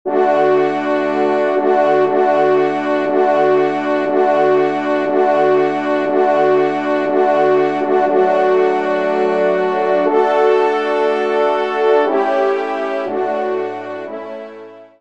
Genre :  Musique Religieuse pour Trois Trompes ou Cors
ENSEMBLE